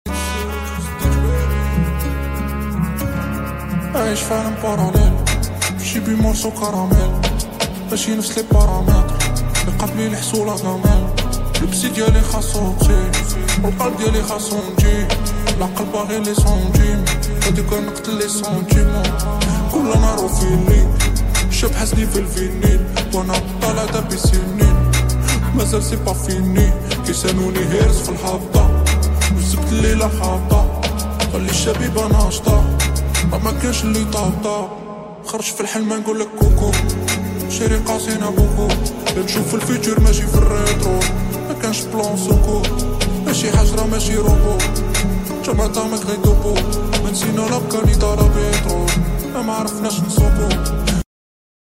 GOLF 7R ACRAPOVIC SOUND MOD Sound Effects Free Download